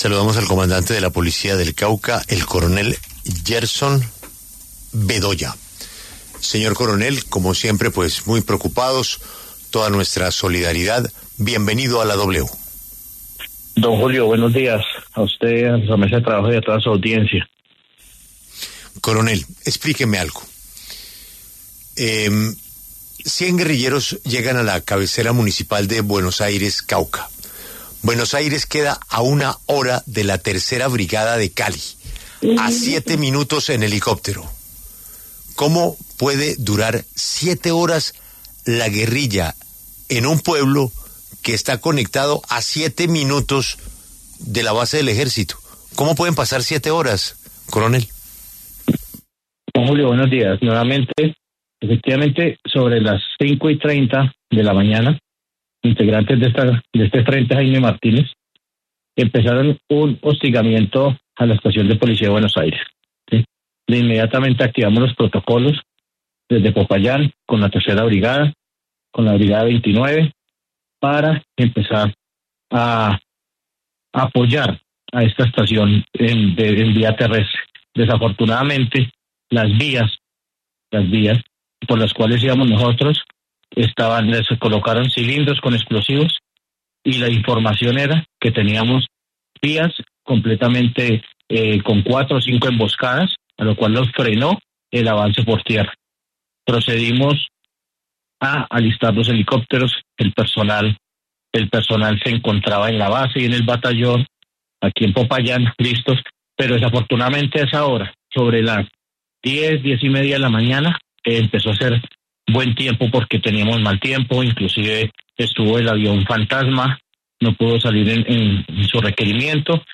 El comandante del Departamento de Policía Cauca, coronel Gerson Bedoya, en entrevista en La W reveló detalles del ataque perpetrado por las disidencias en Buenos Aires, Cauca.